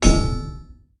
goldenpig_hit_03.ogg